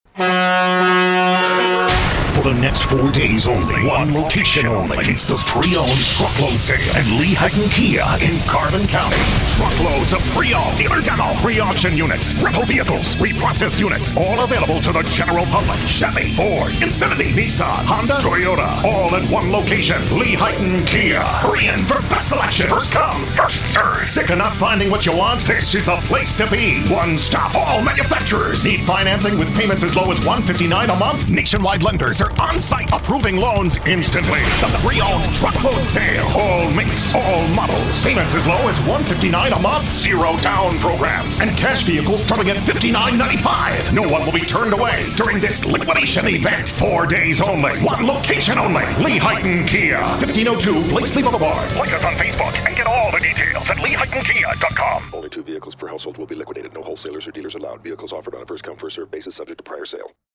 Monster Truck Voice Over
Here is a sample of a monster truck voice over that we did for an automotive dealer. This is one way to crush the competition if you are looking for hard sell radio commercial production.
MonsterTruckVoice.mp3